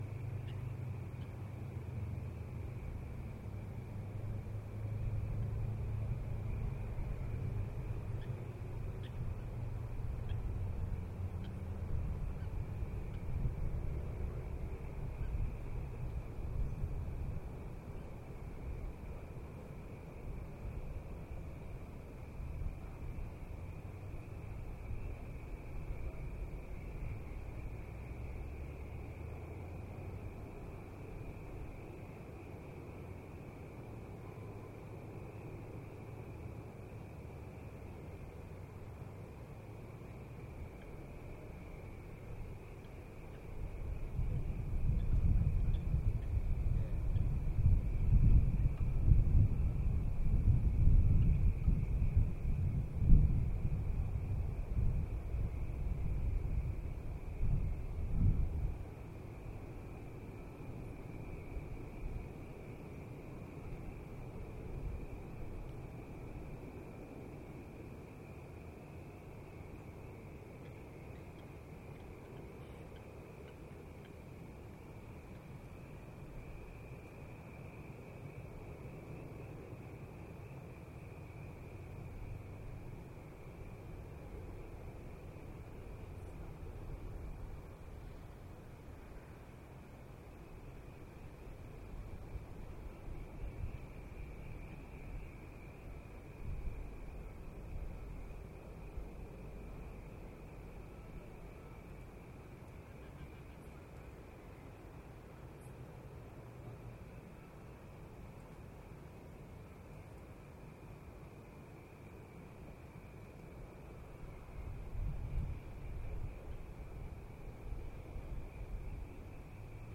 Waveform Sound Archive; There isn't much to hear until you start to listen, Flying fox end of Lake Pertobe, Warrnambool near water.